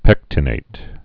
(pĕktə-nāt) also pec·ti·nat·ed (-nātĭd)